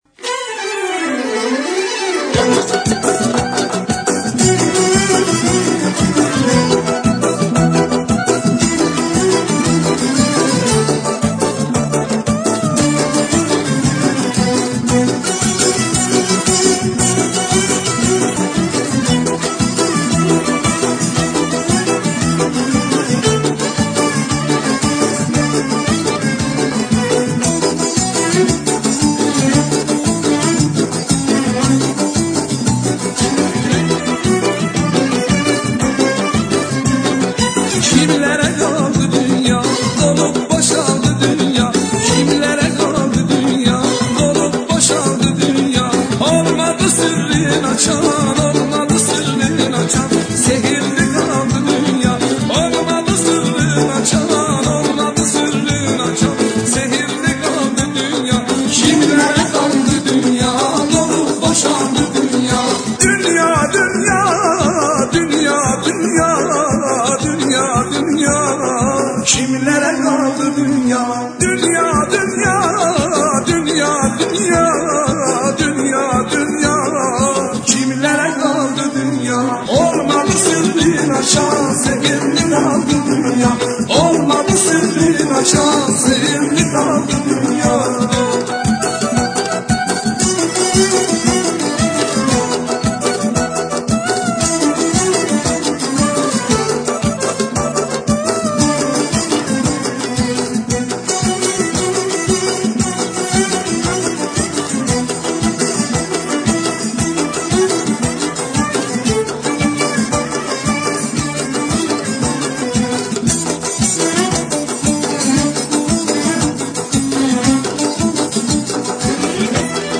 آهنگ ترکی
ریمیکس